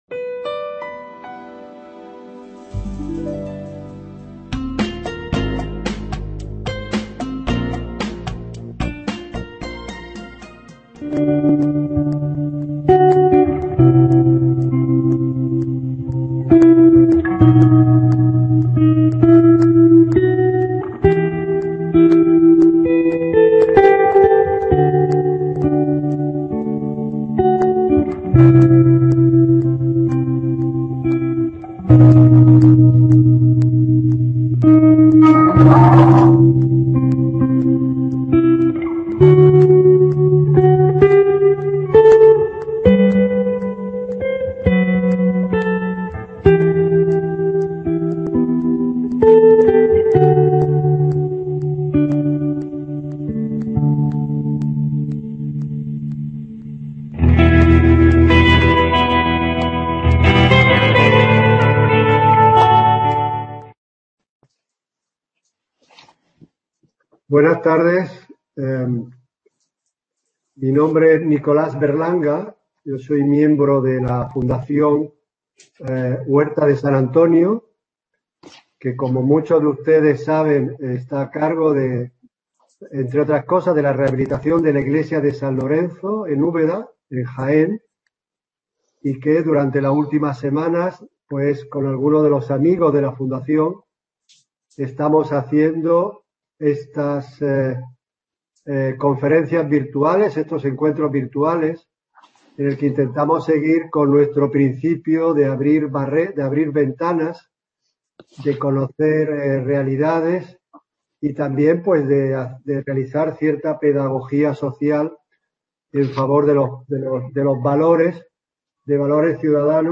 Encuentro virtual organizado por la Fundación Huerta de San Antonio y Reporteros sin Fronteras España, y patrocinado por el Centro asociado de la UNED "Andrés de Vandelvira" en la provincia de Jaén.